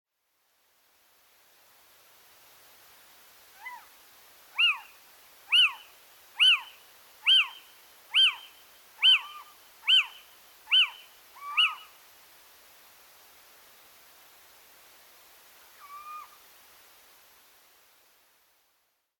Steenuil geluid
De steenuil laat een reeks boeiende klanken horen.
Mannetjes zitten op een vaste uitkijkpost en roepen met tussenpozen van een aantal seconden een langgerekt “kuuup”.
De meest bekende roep is een scherp “WIEuw!” dat ongeveer een halve seconde duurt. Dit geluid verzwakt in toonhoogte en volume.